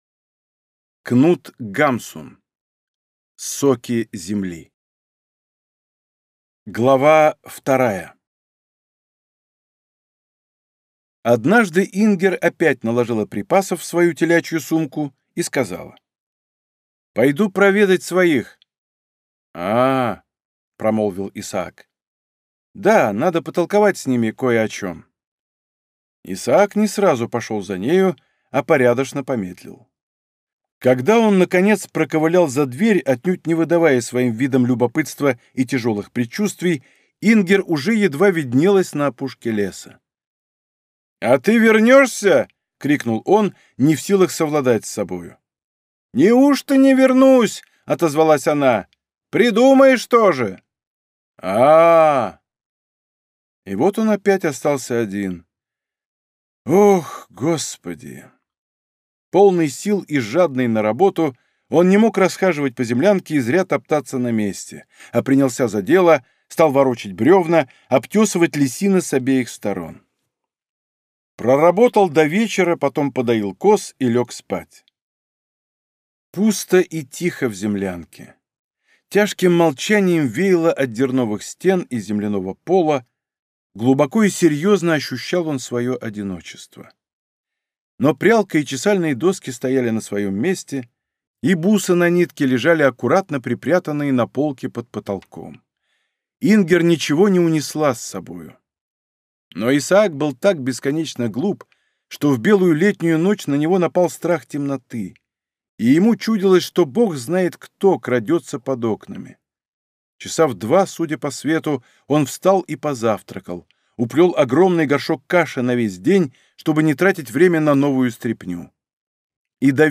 Аудиокнига Соки земли | Библиотека аудиокниг